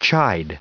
added pronounciation and merriam webster audio